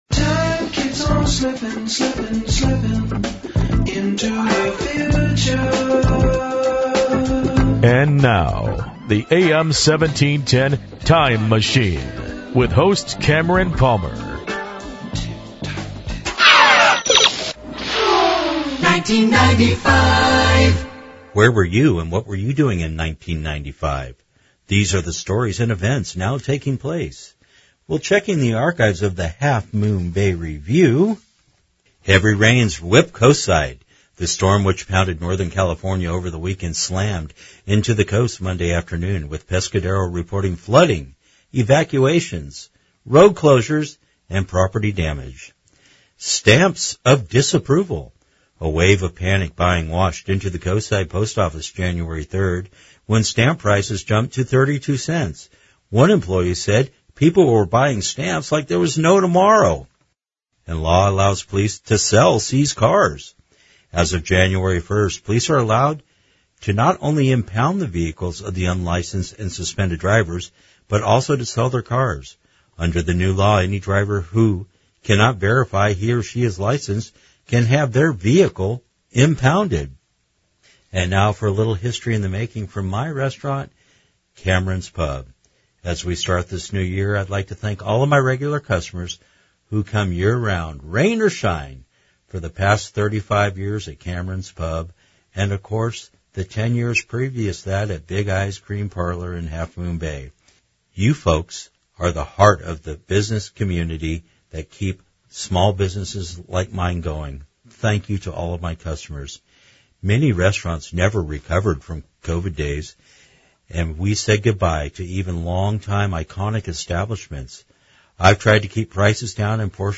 The show winds up with a hit song from the chosen year – truly a short trip down memory lane.